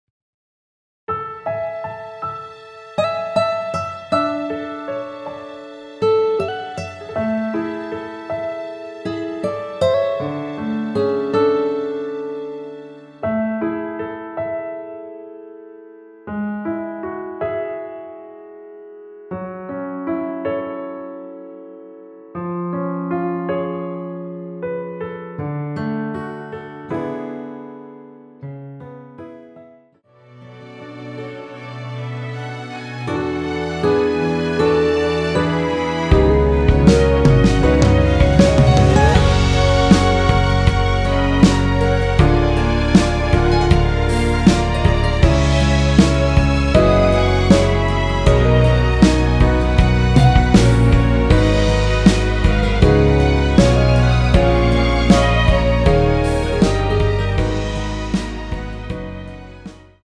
원키에서(+5)올린 (1절앞+후렴)으로 진행되는 MR입니다.
◈ 곡명 옆 (-1)은 반음 내림, (+1)은 반음 올림 입니다.
앞부분30초, 뒷부분30초씩 편집해서 올려 드리고 있습니다.